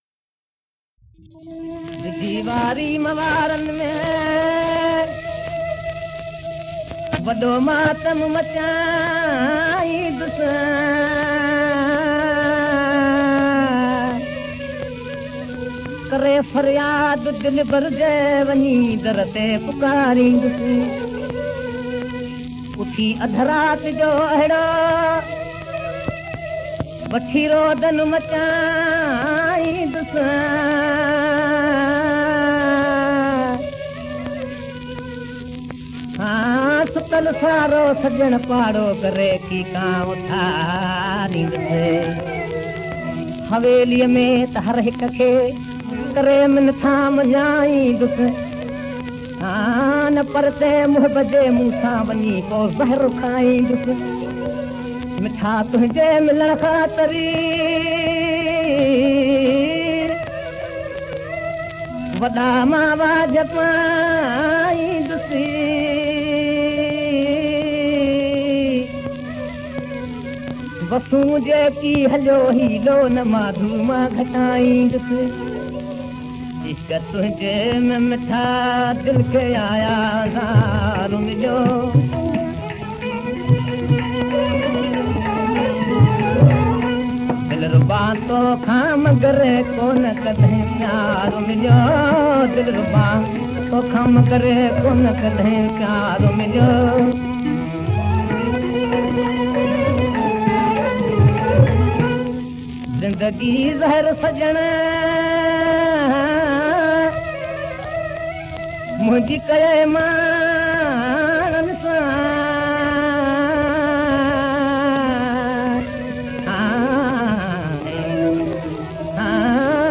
Converted from very old Gramophone records.